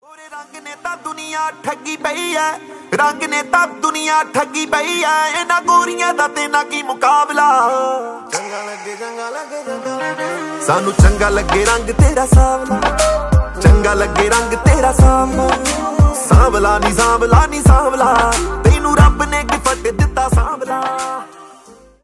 soulful vocals
female vocalist
duet